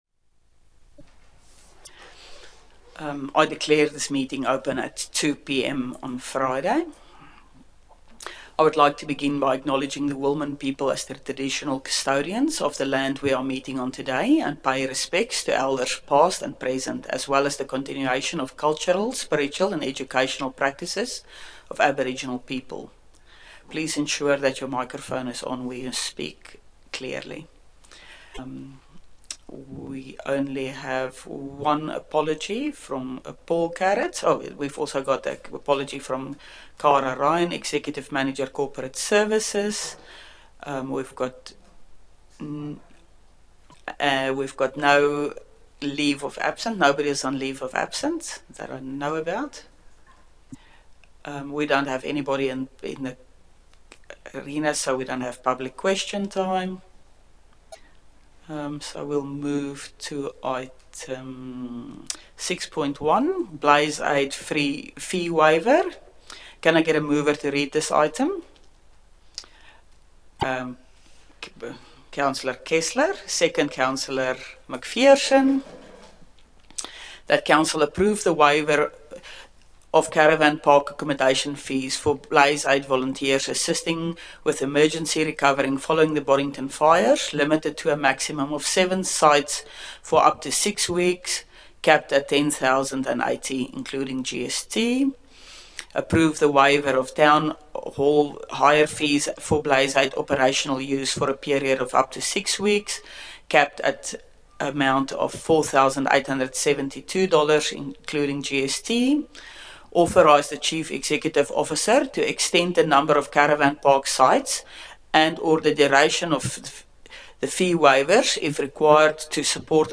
Special Council Meeting 9 January 2026 » Shire of Boddington